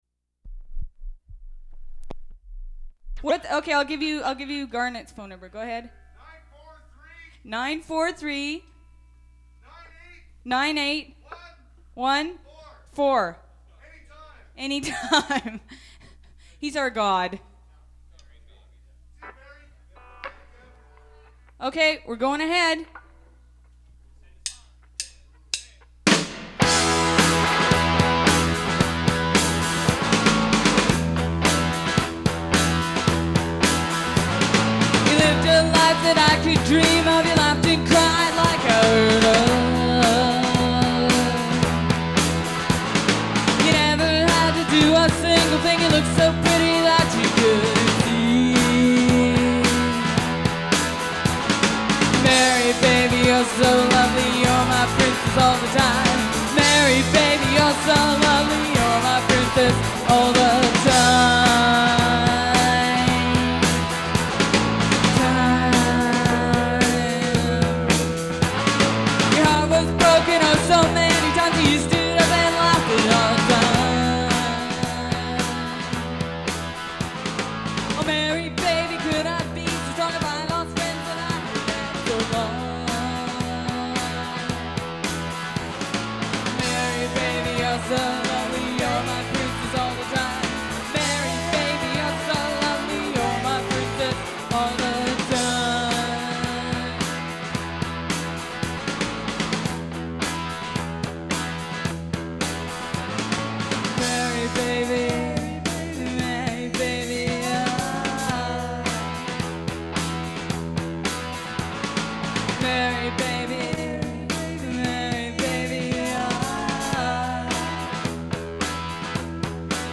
Recording of a live performance